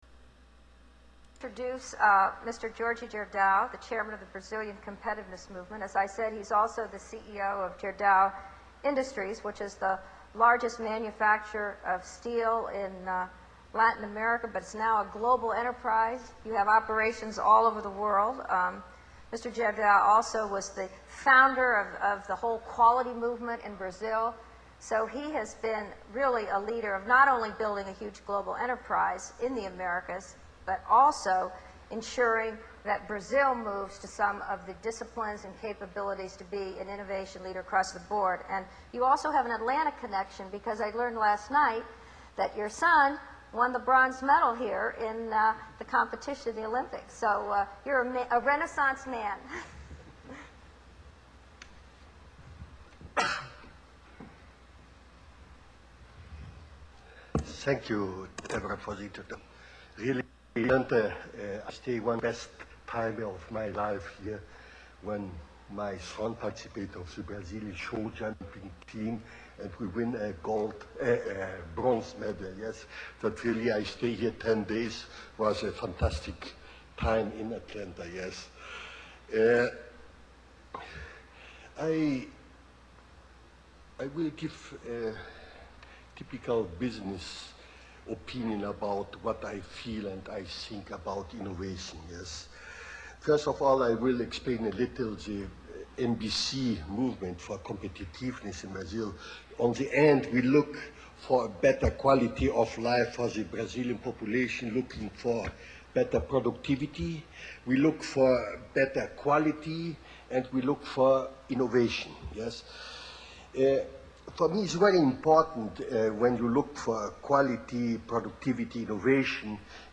Most humans don't understand meta-language so the monsters can speak openly about what they are doing - and the humans think they know what is being said, but they don't. Humans are the commodities that Gerdau referred to in the beginning of his speech.